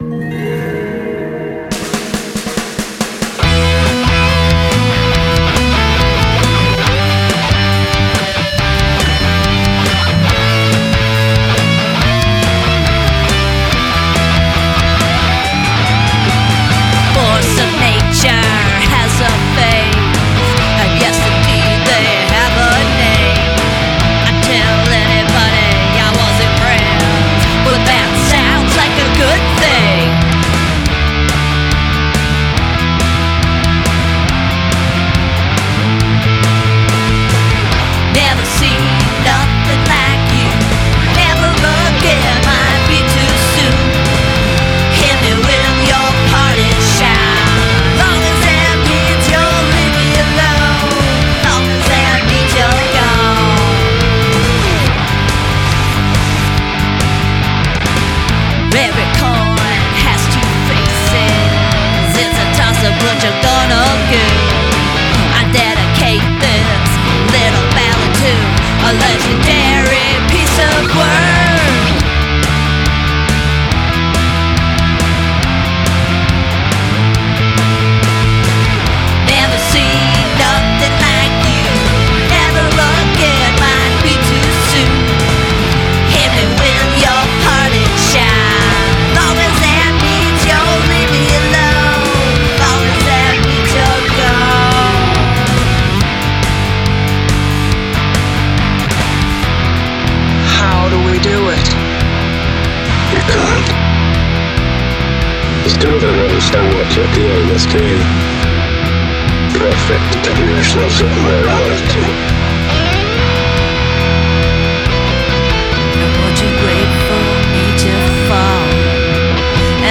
that sound in the beginning is cool.